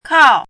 kào
国际音标：kʰɑu˨˩˦;/kʰɑu˥˧
kào.mp3